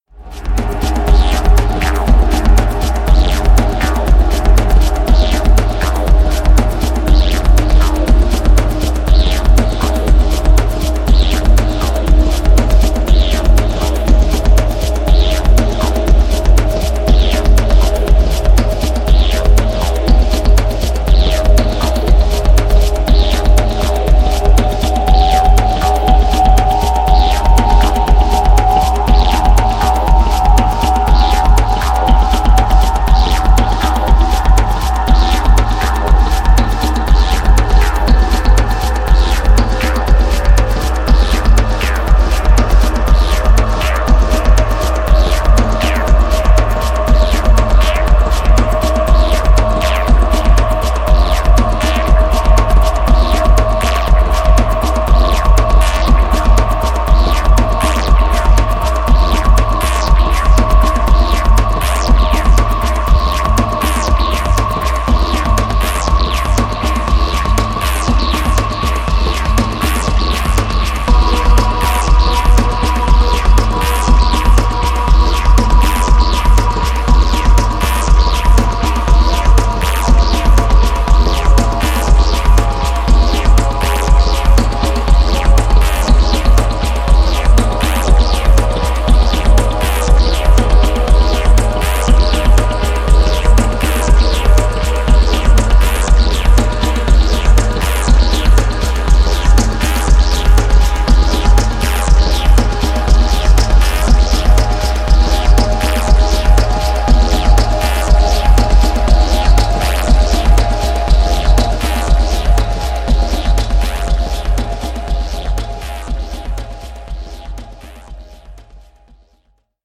オーガニックなパーカッションとドローンフレーズが深遠なムードの